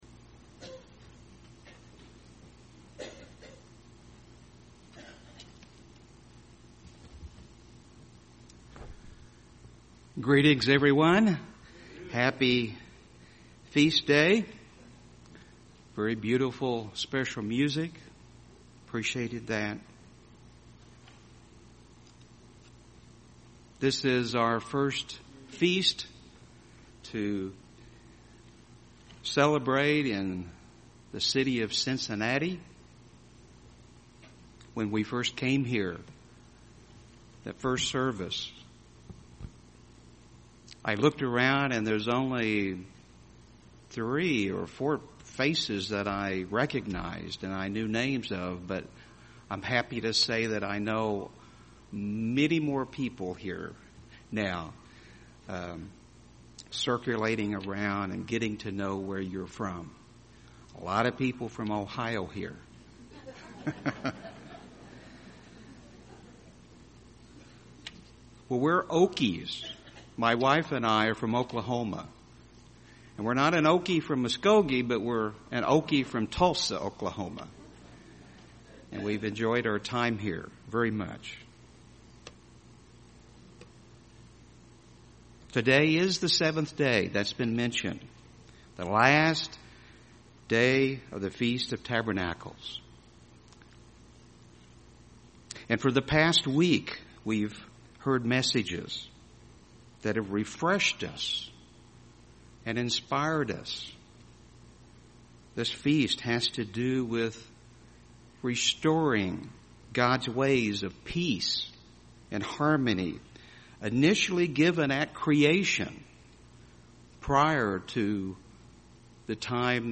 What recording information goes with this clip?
This sermon was given at the Cincinnati, Ohio 2017 Feast site.